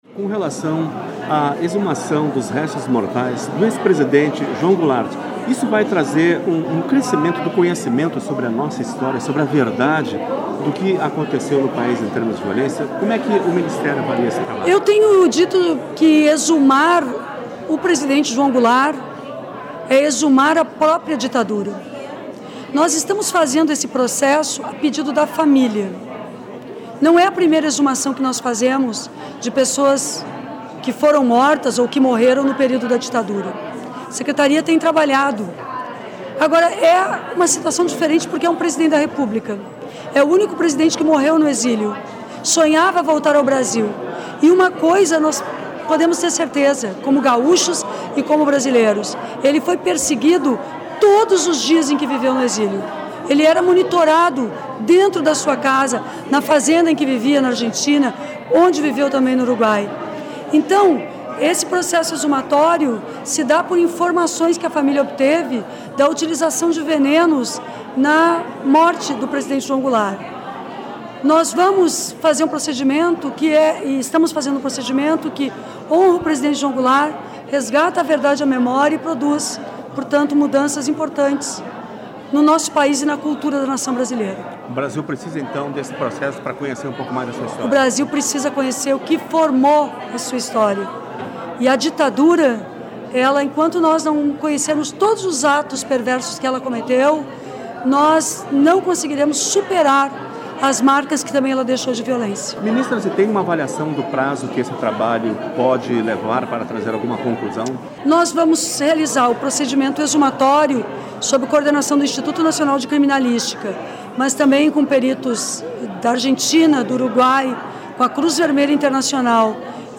ENTREVISTA - Exumar o corpo de Jango é exumar a ditadura, diz Maria do Rosário
A ministra dos Direitos Humanos, Maria do Rosário, fala sobre o processo de exumação do presidente João Goulart, que deverá ser realizada entre os dias 13 e 14 de novembro.